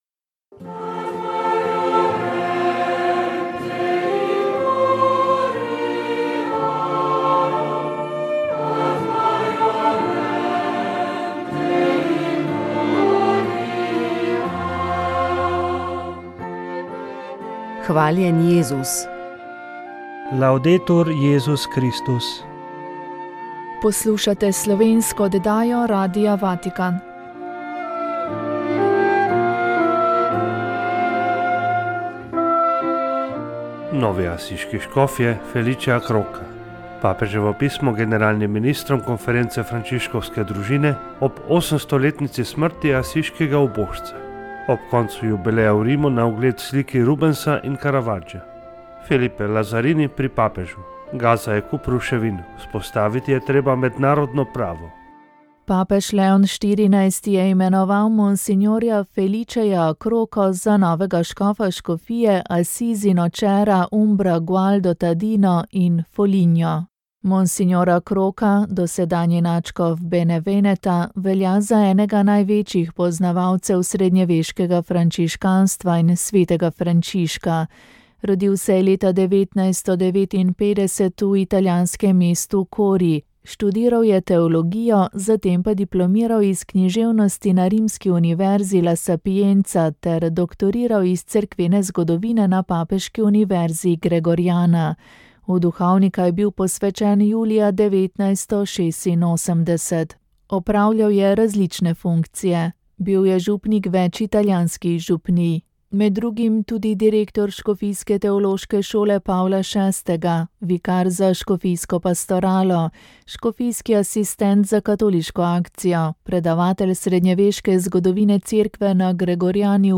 Še tri smo prebrali v tokratni oddaji.